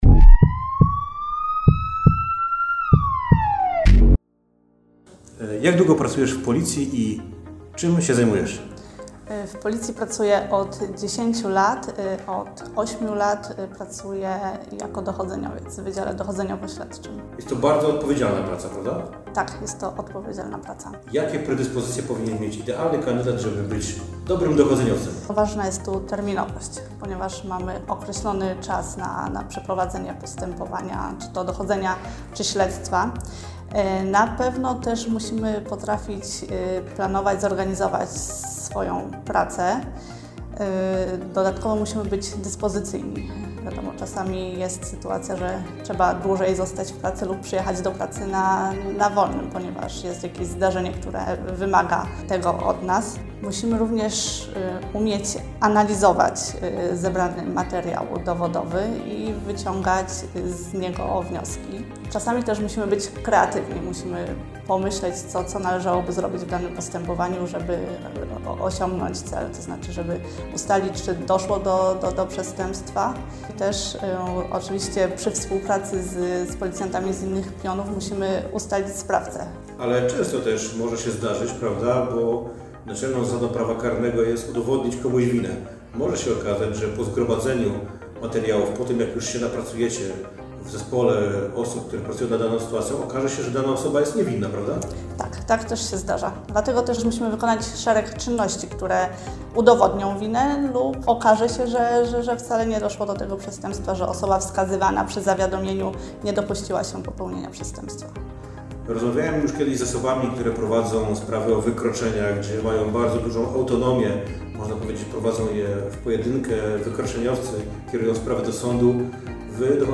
Rozmowa z policjantką, która od wielu lat związana jest z tym pionem.